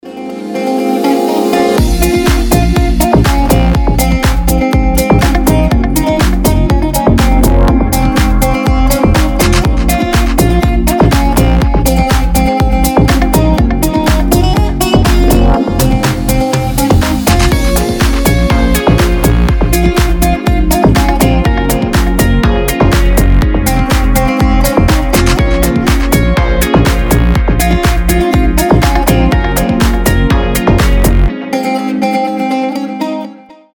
• Качество: 320, Stereo
гитара
deep house
восточные мотивы
мелодичные
без слов